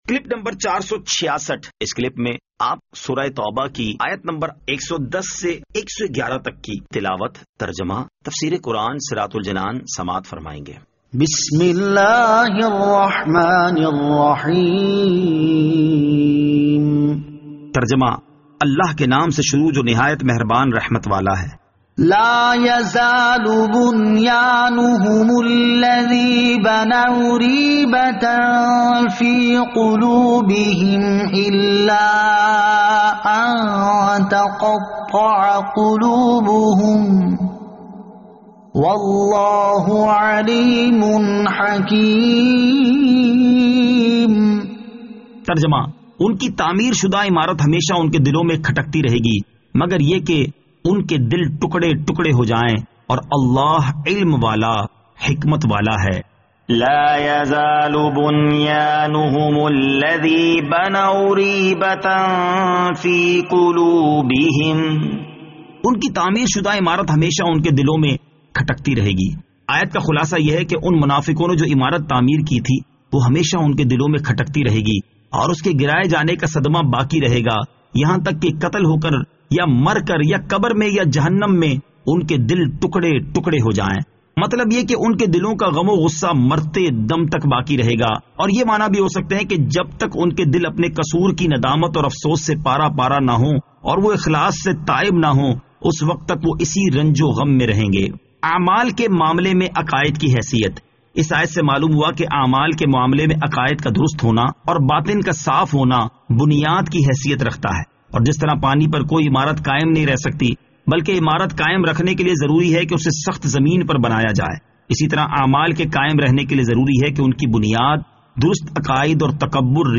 Surah At-Tawbah Ayat 110 To 111 Tilawat , Tarjama , Tafseer